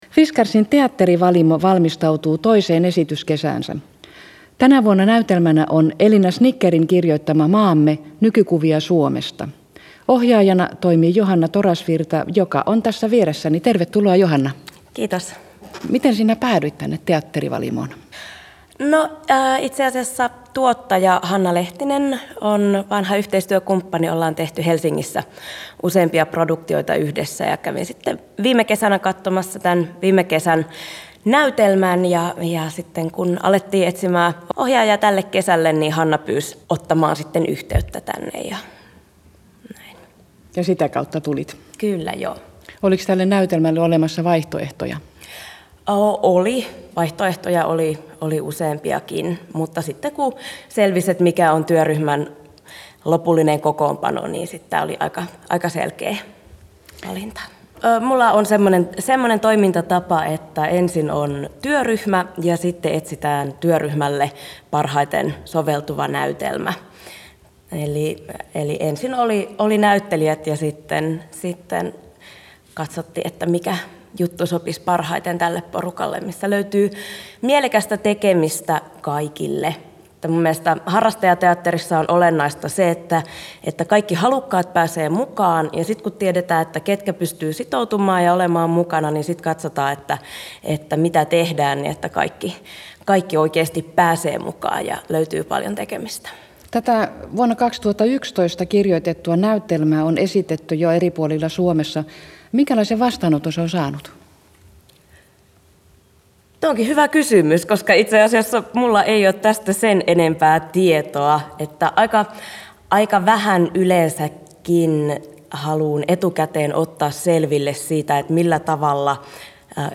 GALLUP: Maamme – Nykykuvia Suomesta